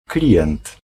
Ääntäminen
Ääntäminen Tuntematon aksentti: IPA: /ˈklijɛn̪t̪/ Haettu sana löytyi näillä lähdekielillä: puola Käännös Konteksti Ääninäyte Substantiivit 1. customer 2. client tietojenkäsittely US Suku: m .